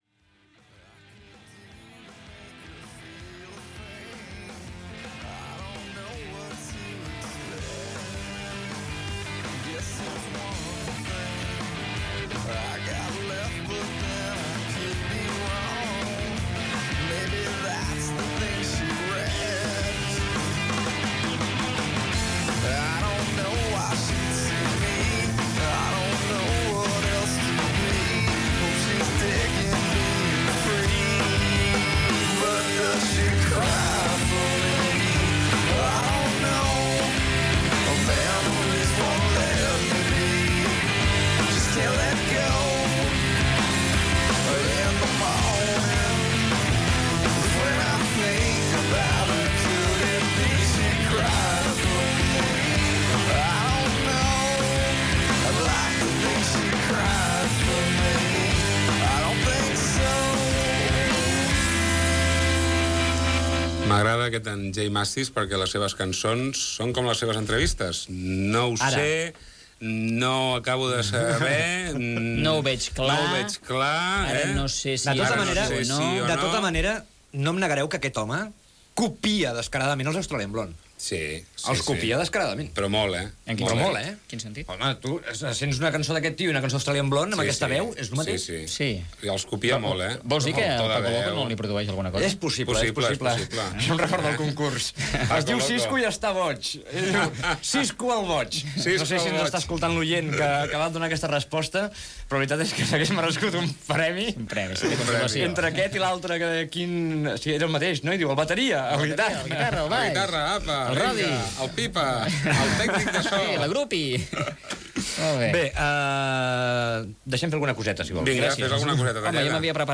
Tema musical, agenda de concerts, tema musical, comentari sobre Internet, entrevista al music J.Mascis, tema musical, agenda de concerts, tema musical Gènere radiofònic Musical